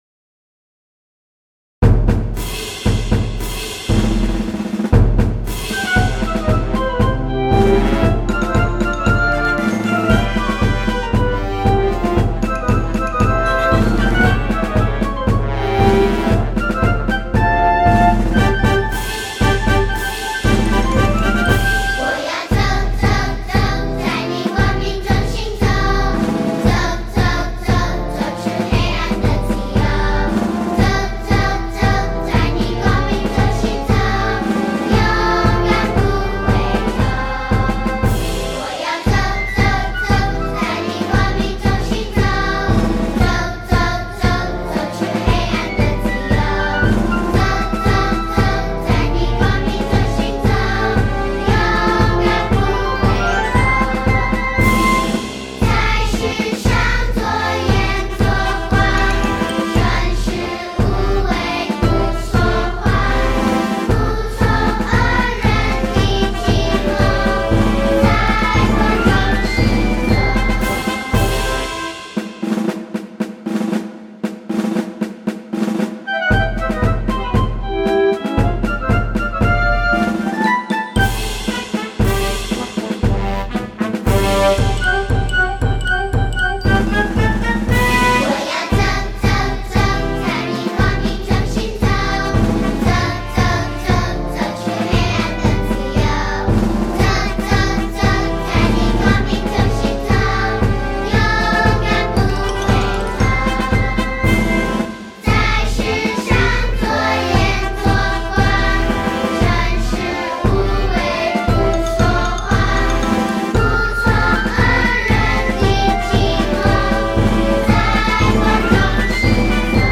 跟唱建议 动作跟唱，有视频和音频两部分。